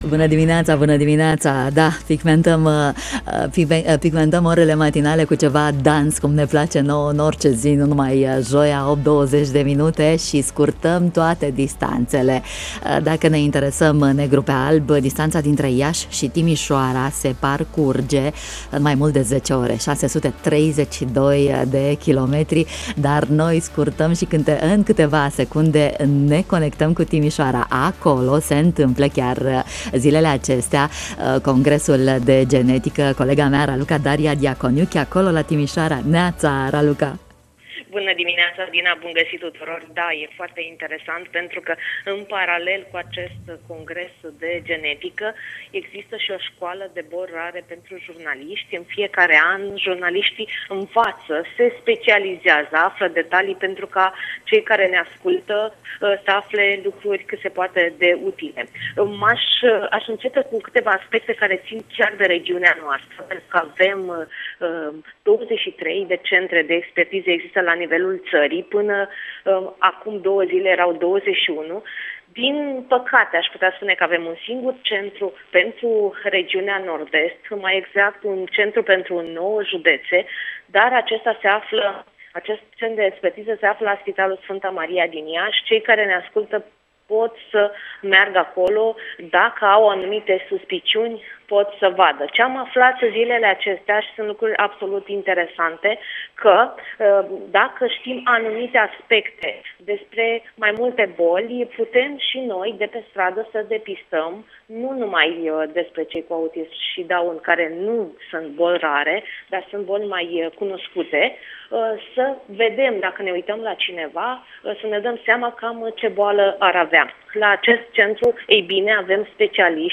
ne-a vorbit în matinalul Radio România Iaşi